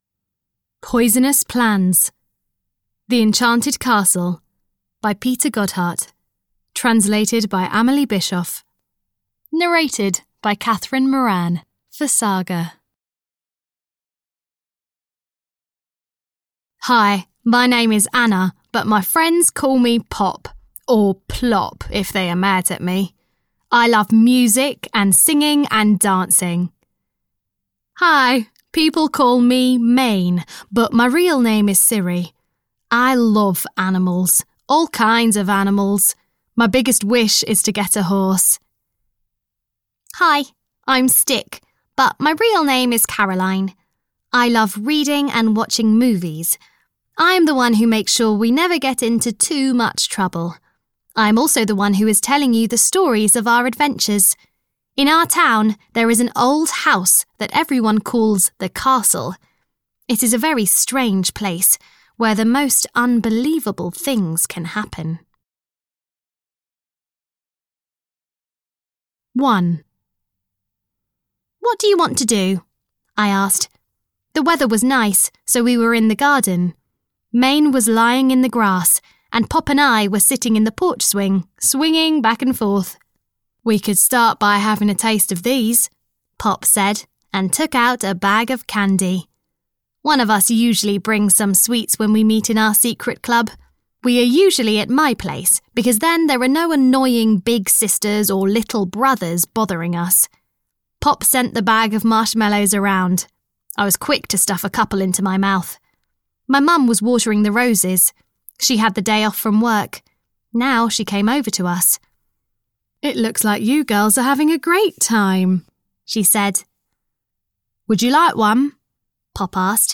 The Enchanted Castle 4 - Poisonous Plans (EN) audiokniha
Ukázka z knihy